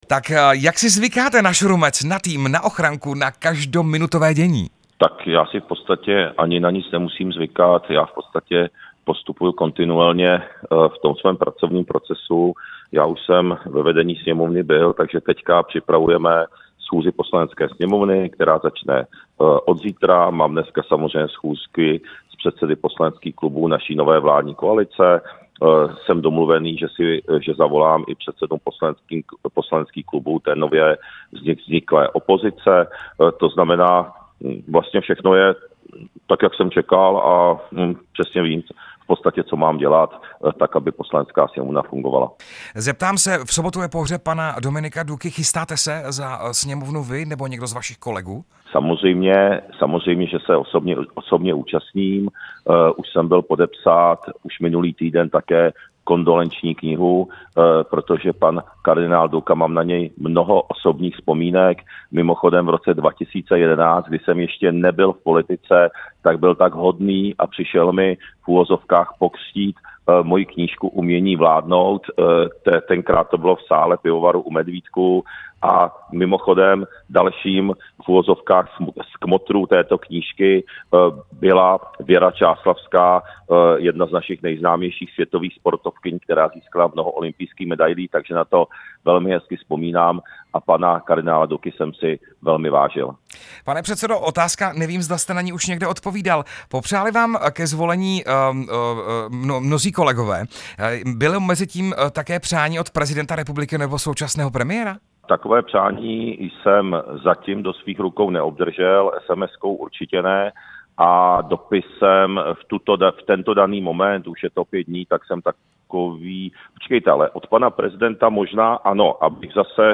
Rozhovor s předsedou Poslanecké sněmovny Tomiem Okamurou